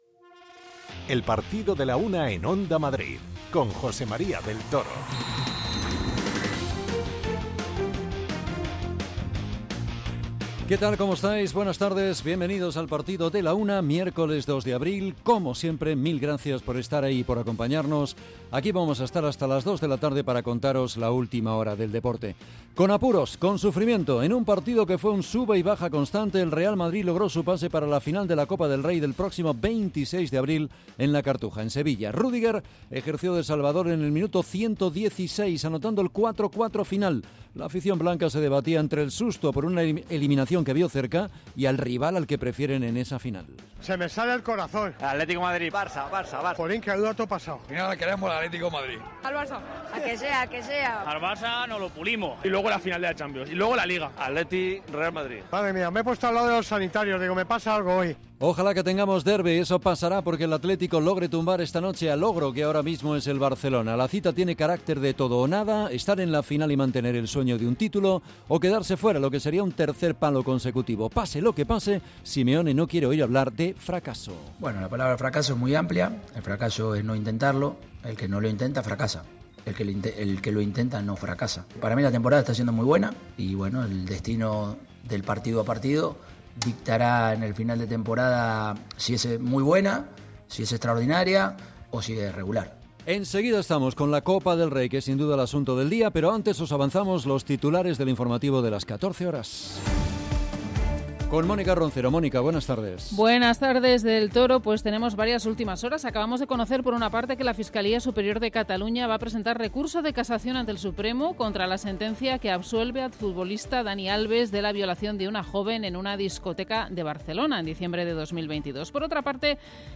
Escuchamos a Ancelotti, Rudiger, Endrick y Tchouameni. 4-4.